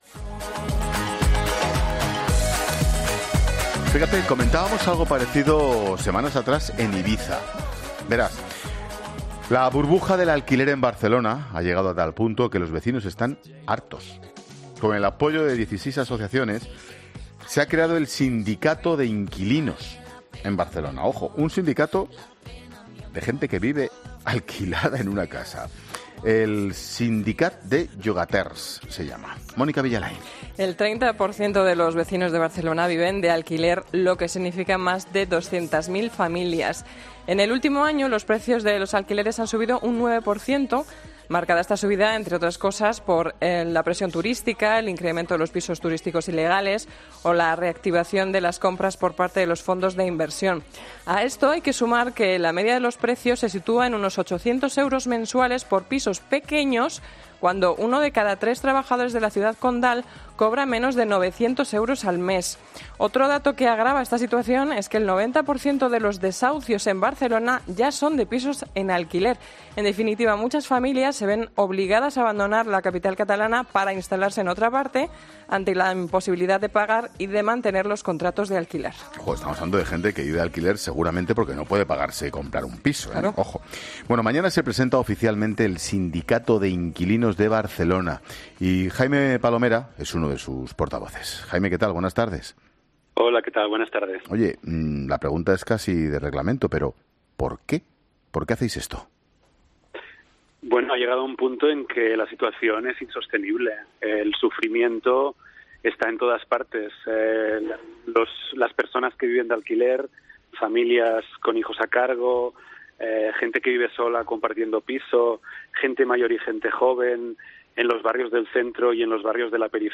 Sus promotores explican en 'La Tarde' con Ángel Expósito, que surgen para luchar contra "los elevados precios" de los inmuebles